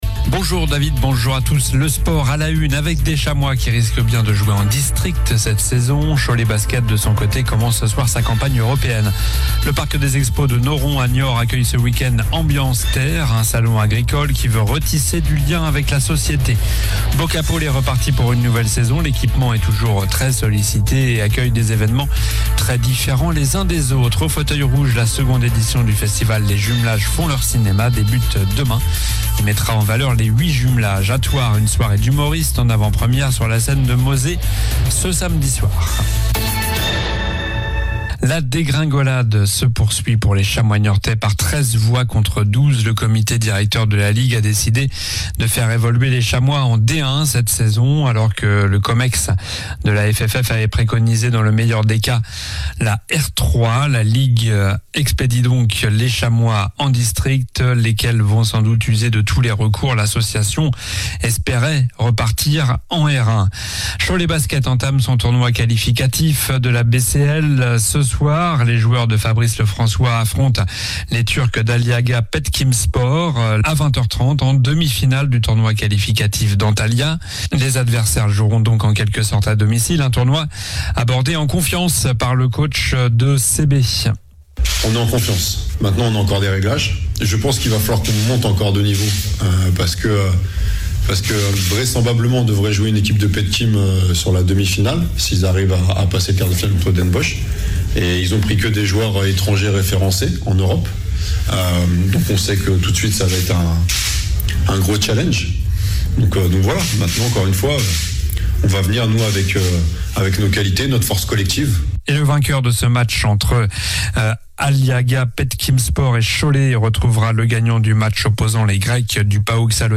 Journal du jeudi 19 septembre (midi)